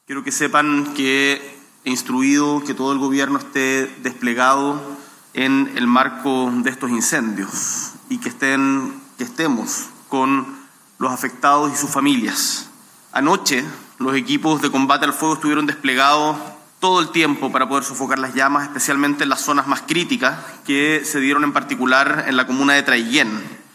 Por otro lado, y desde Punta Arenas, en el marco de la inauguración del Foro Internacional de Inversiones 2025, habló el presidente de la República, Gabriel Boric, y se refirió a la situación de los incendios forestales en la zona sur de nuestro país y en el caso particular de Traiguén.